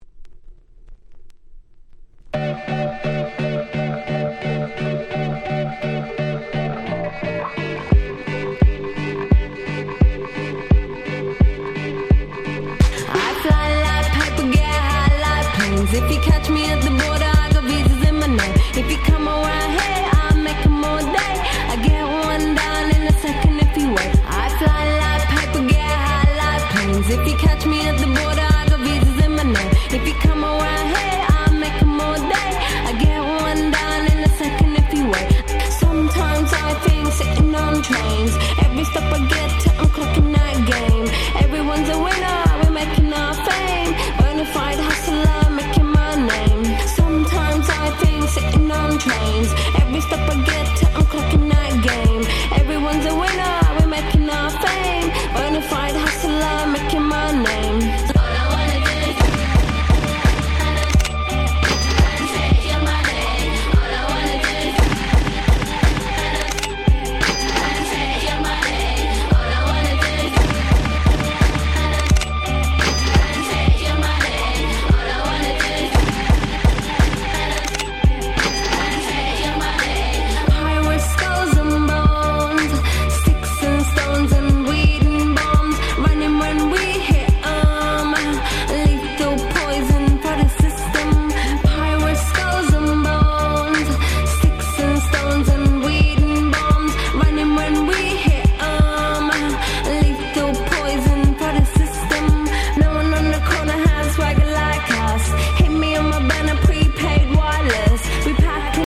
08' Super Hit Hip Hop / R&B !!